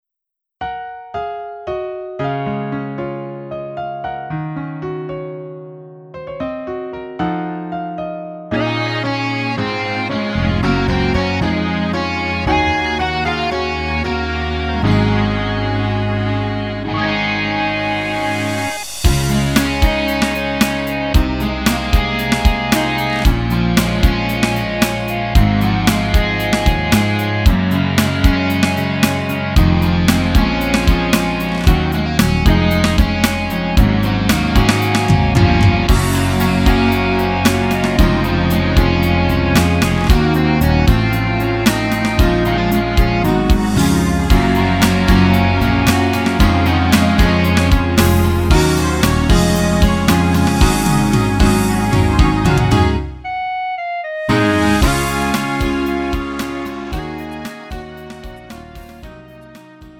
음정 원키 4:05
장르 구분 Lite MR